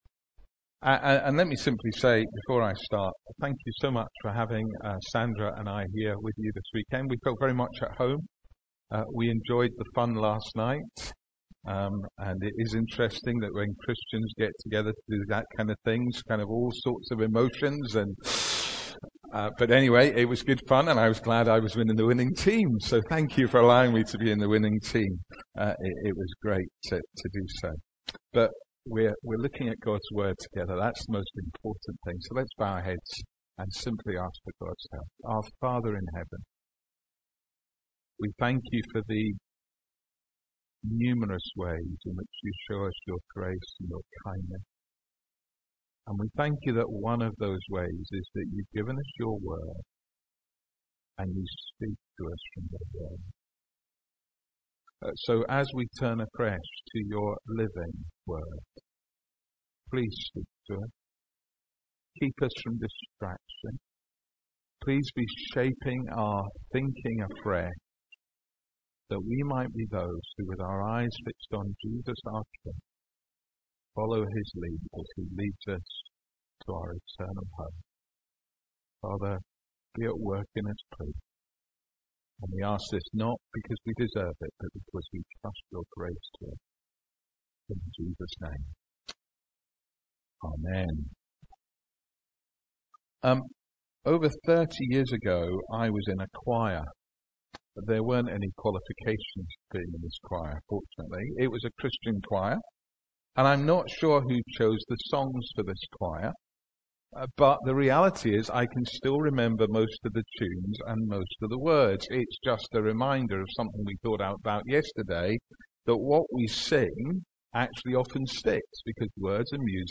Our church weekend 2018 was held at Cumnor House School near Danehill on the 29th & 30th September 2018.
gave the main talks from 9 of the Psalms of ascents entitled the Pilgrims Playlist Apologies the sound quality is not up to our usual standard